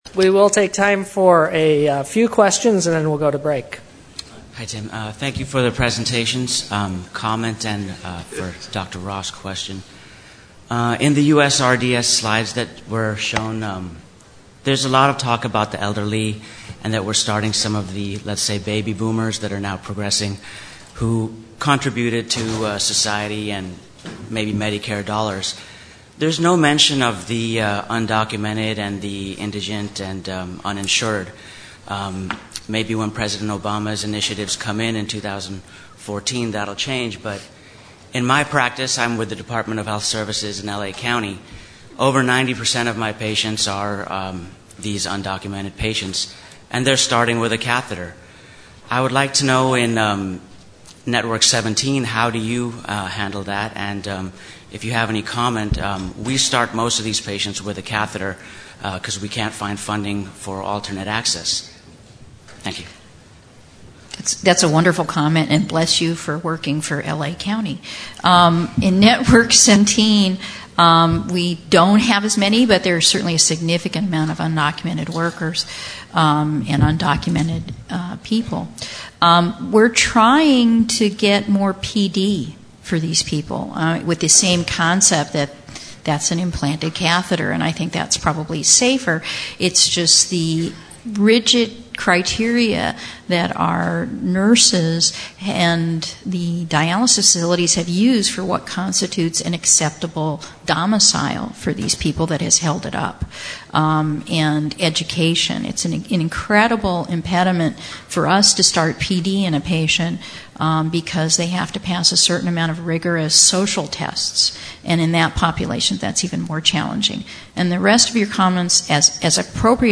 Discussions